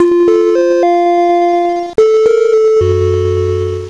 Crash Sounds:
Crash_PowerMac_LC ....................Power Macintosh 5200/75LC